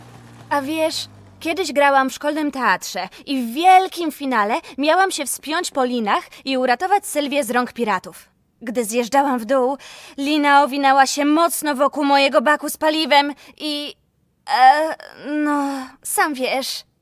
Female 5-20 lat
Fragment bajki